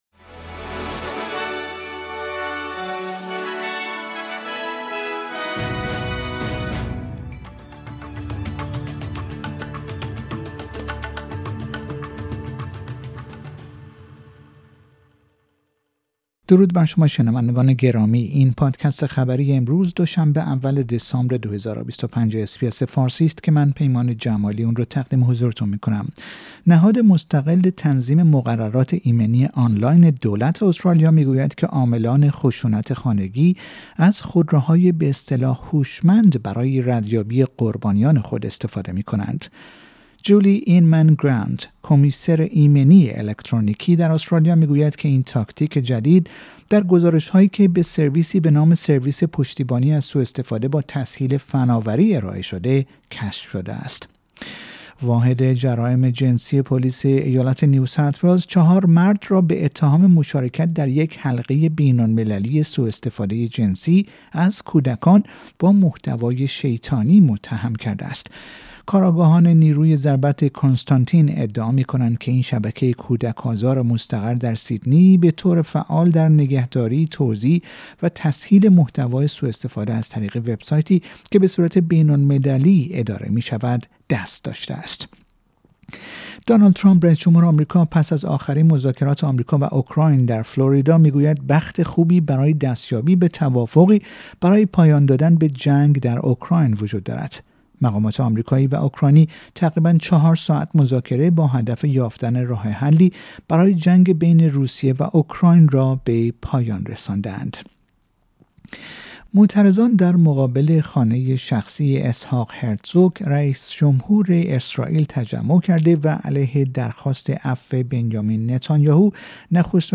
در این پادکست خبری مهمترین اخبار روز دو شنبه اول دسامبر ارائه شده است.